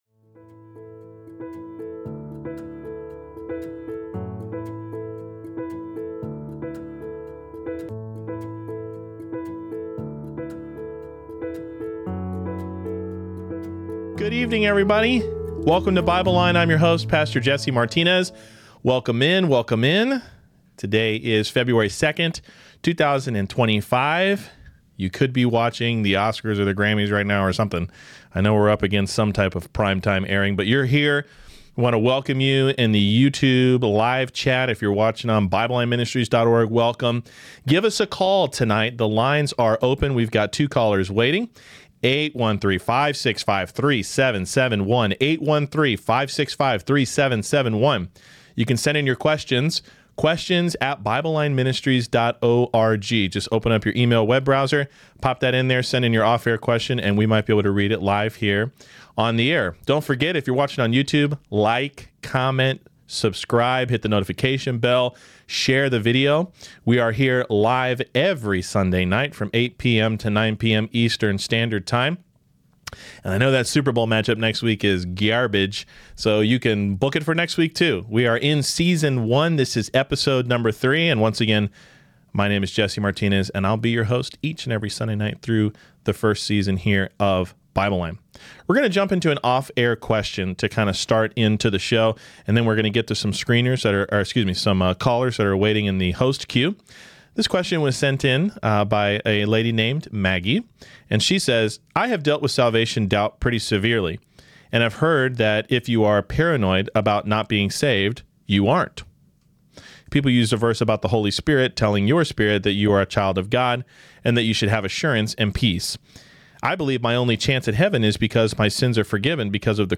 BibleLine LIVE QNA Replay | Loneliness, Unforgivable Sin, Crossless Gospel, Perseverance and more!